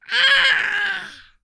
Child_zombie_hurt_2.wav